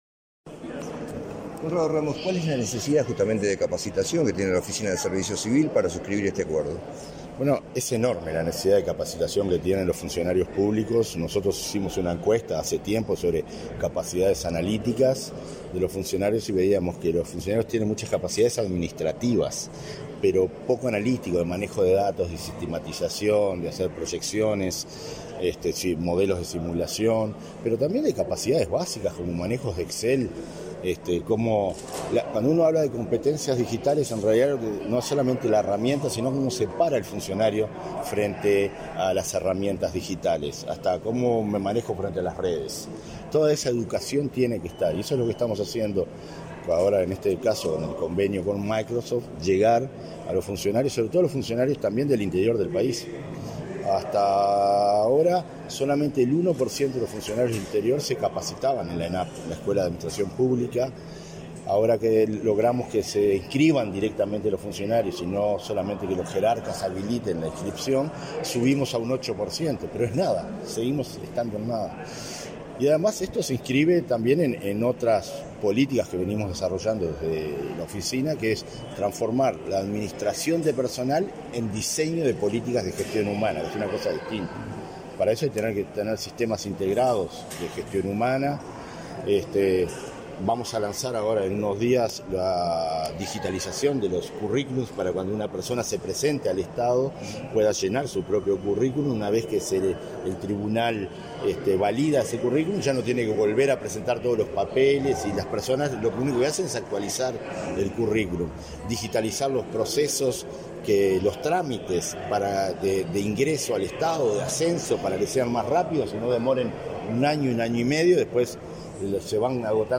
Declaraciones del director de la ONSC, Conrado Ramos